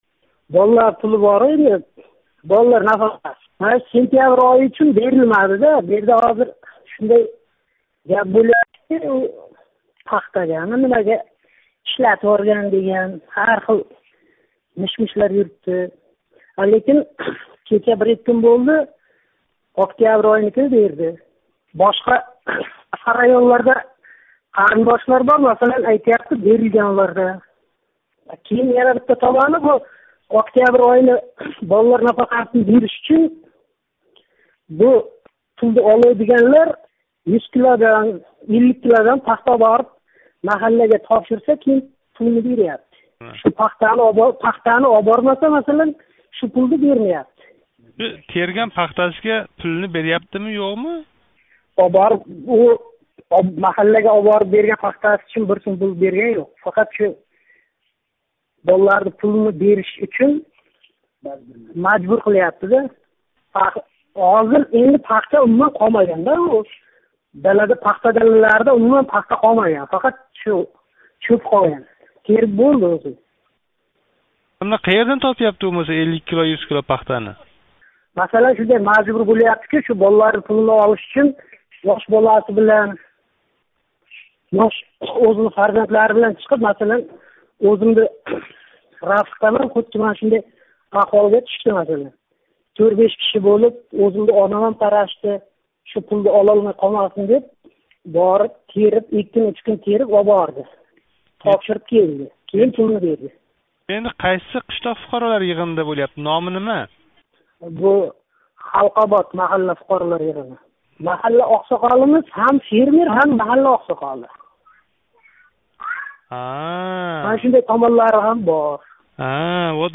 Учтепа пахта қабул қилиш пункти ходими билан суҳбат